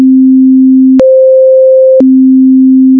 With Goldwave for example, you can produce sounds, which have, say 1 sec of 261.63 Hz (middle c) in the beginning, then 1 sec of 523.26 Hz (one octave higher c) and at the end 1 sec of 261.63 Hz again.
It's a 132396 bytes long, 22.05 kHz 16-bit Mono sample, named TEST.WAV.
At middle C4 it takes exactly 3 secs to play the sound.